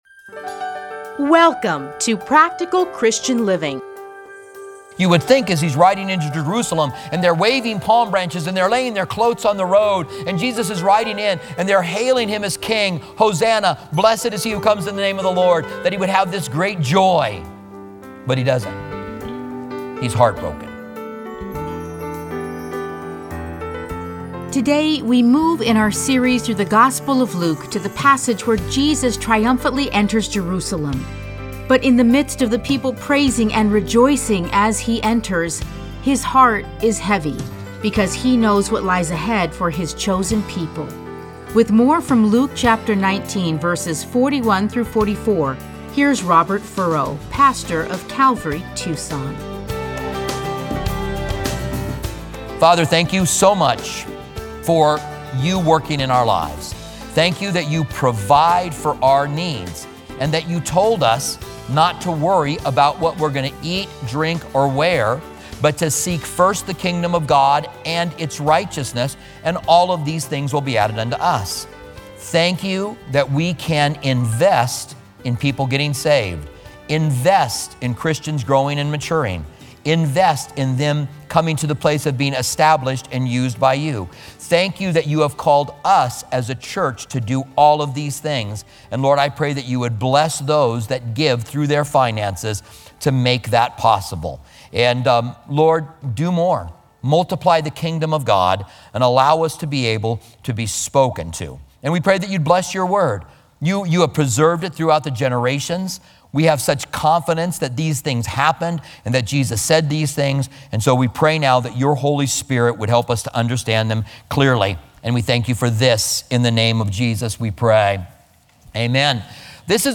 Listen to a teaching from Luke 19:41-44.